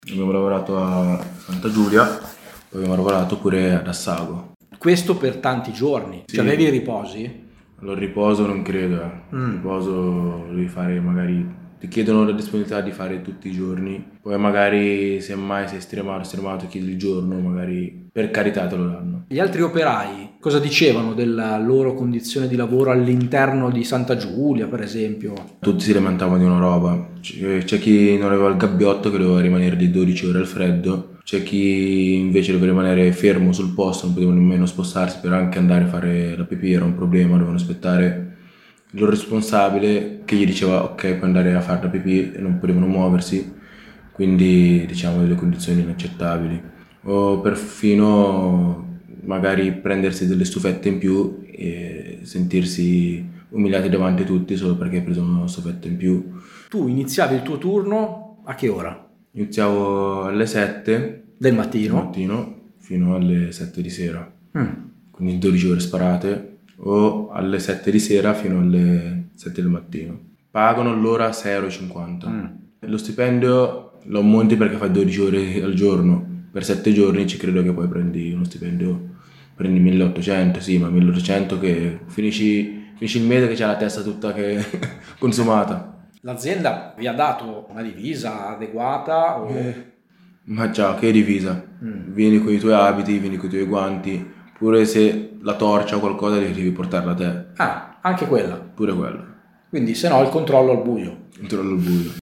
Olimpiadi, contratto part-time ma lavora 12 ore al giorno. La testimonianza di un vigilante a Radio Popolare